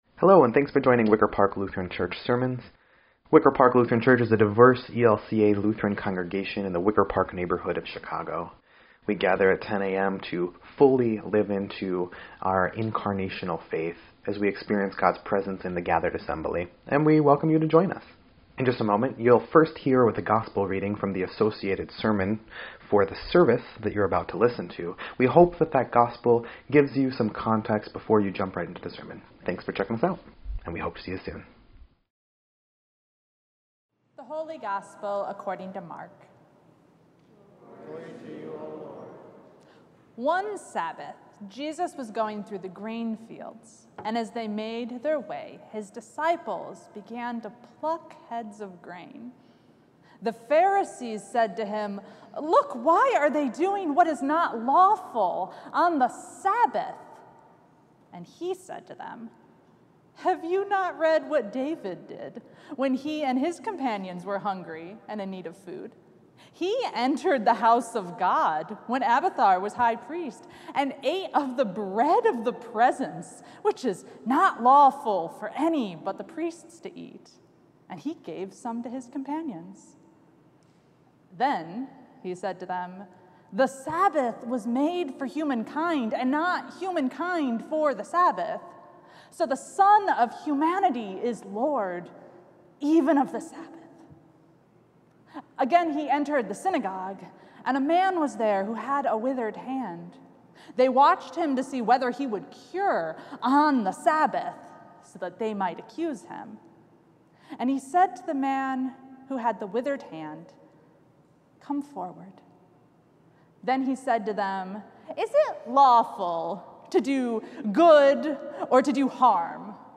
6.2.24-Sermon_EDIT.mp3